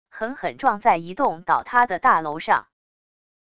狠狠撞在一栋倒塌的大楼上wav音频